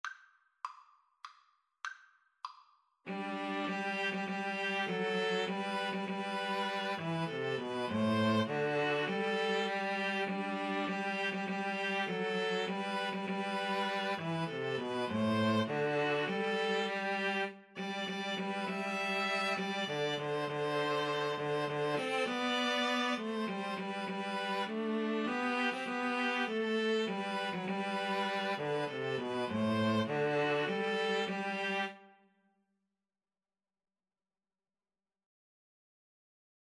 Free Sheet music for Piano Trio
G major (Sounding Pitch) (View more G major Music for Piano Trio )
3/4 (View more 3/4 Music)
Traditional (View more Traditional Piano Trio Music)